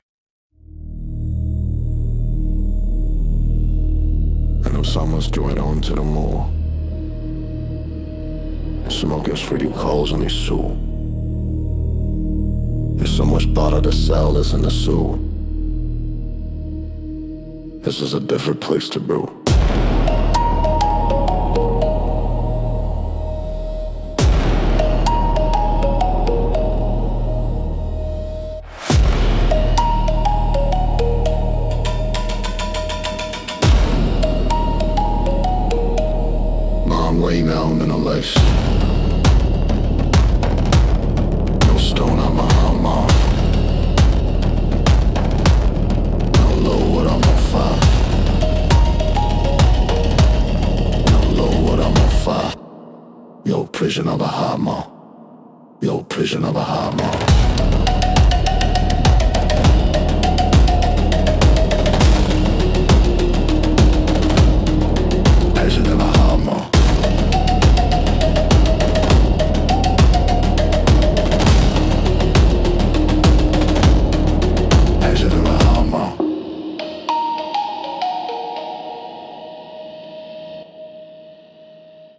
Instrumental - Bones of the Earth - 1.22